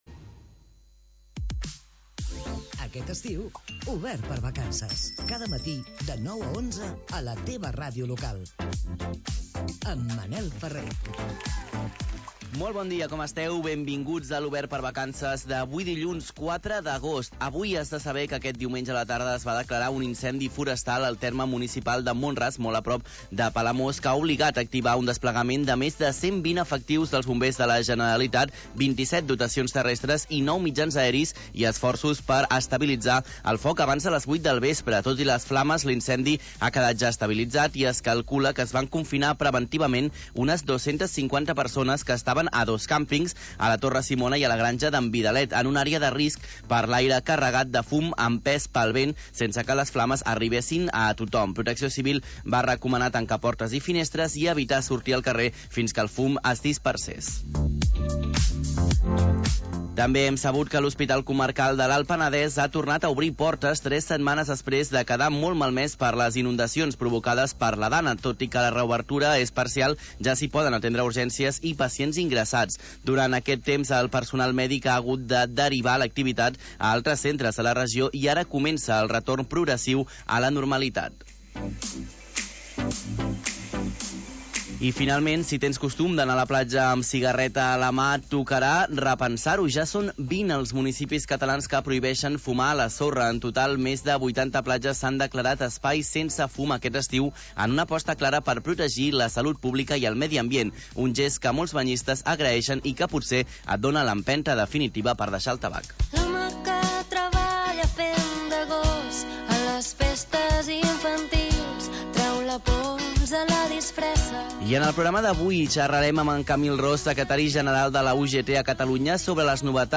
Magazín territorial d'estiu